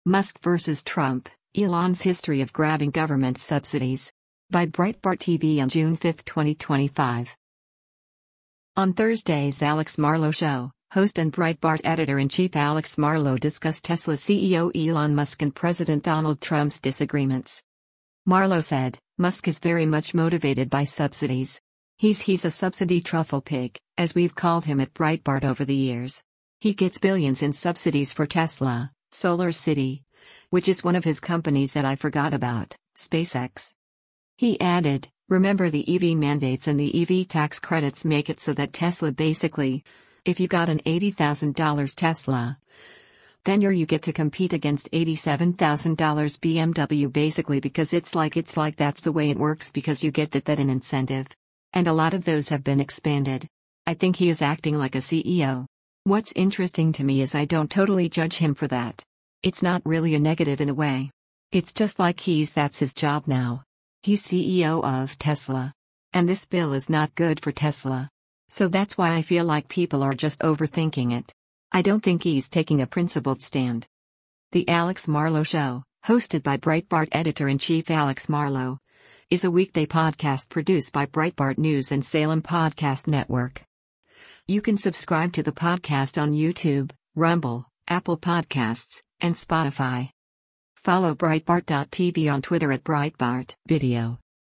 On Thursday’s “Alex Marlow Show,” host and Breitbart Editor-in-Chief Alex Marlow discussed Tesla CEO Elon Musk and President Donald Trump’s disagreements.